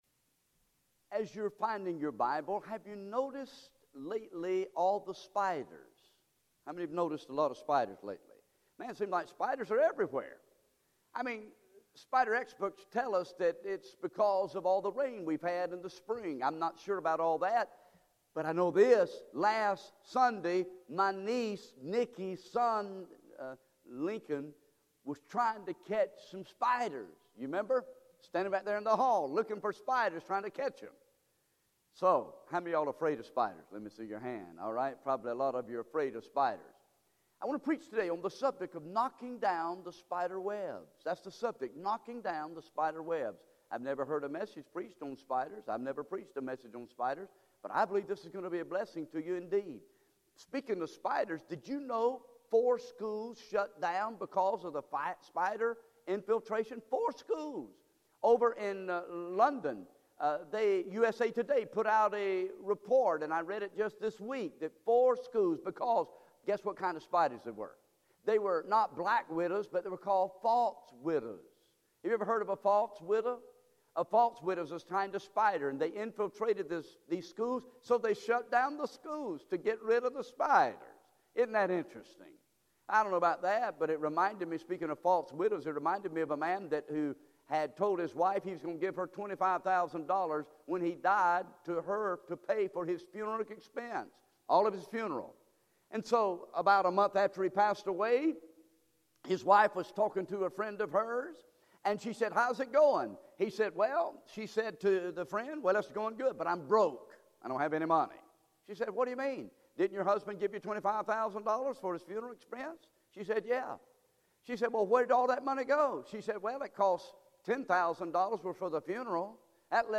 Sermons Archive | Page 3 of 38 | New Rocky Creek Baptist Church